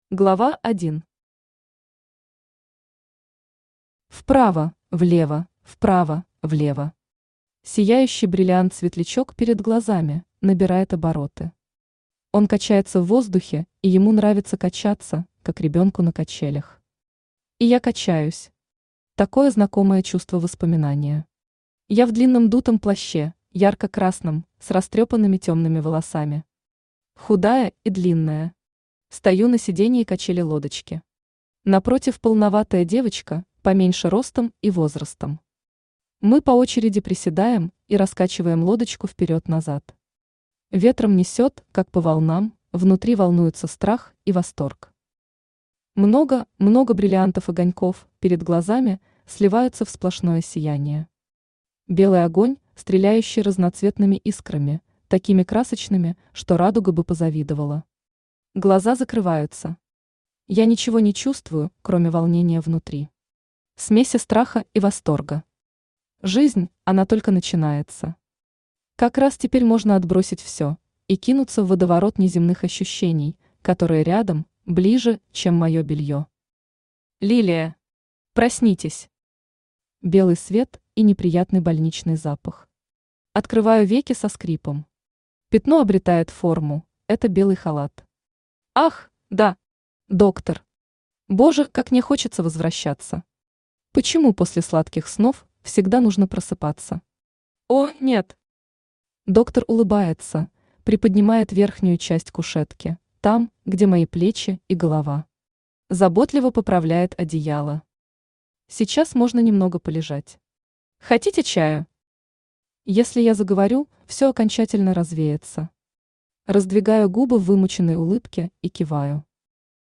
Аудиокнига Милый белый свет | Библиотека аудиокниг
Aудиокнига Милый белый свет Автор Юлия Валерьевна Шаманская Читает аудиокнигу Авточтец ЛитРес.